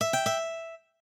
lute_ege.ogg